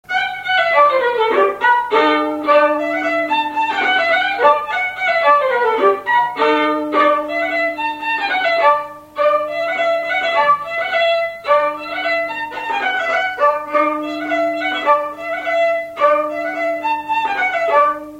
Résumé instrumental
gestuel : danse
Pièce musicale inédite